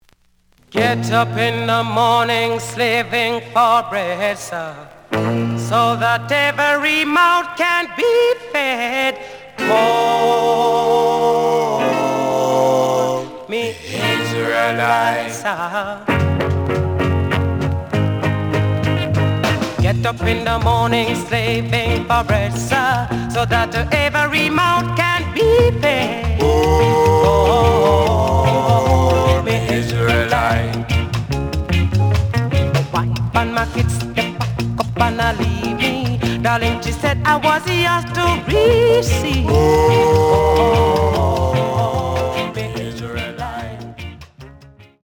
The audio sample is recorded from the actual item.
●Genre: Rock Steady